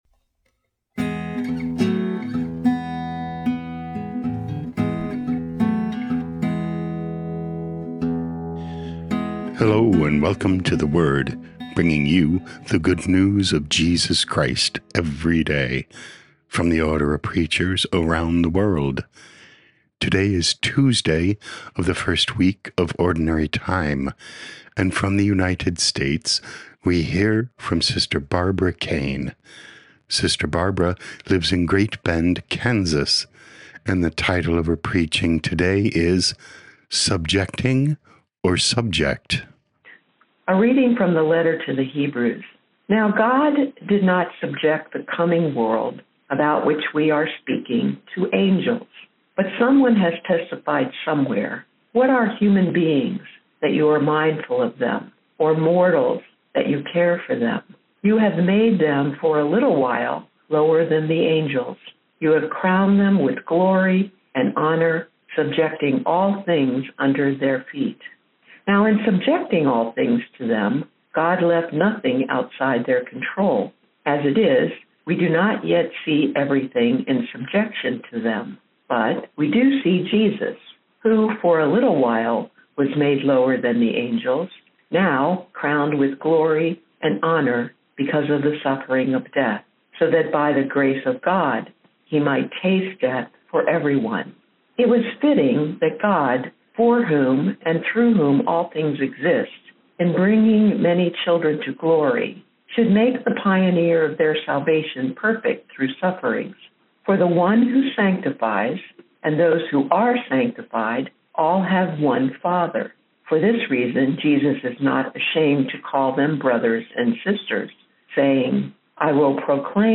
daily homilies from the Order of Preachers